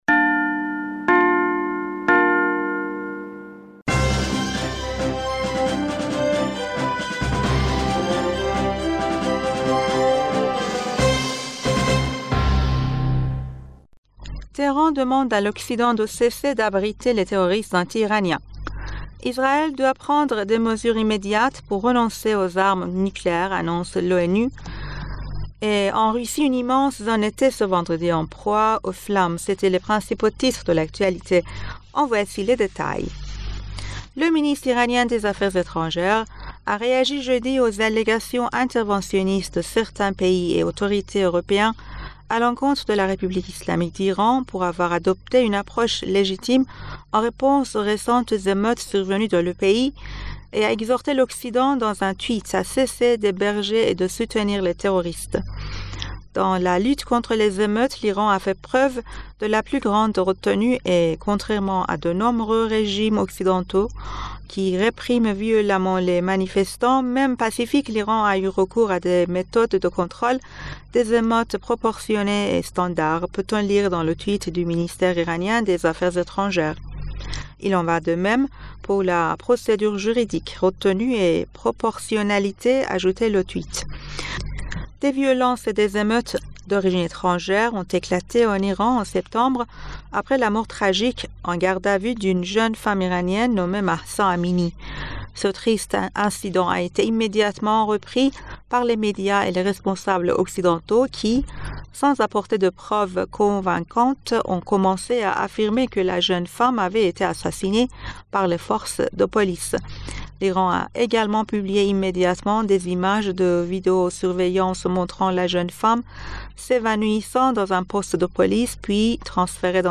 Bulletin d'information du 09 Décembre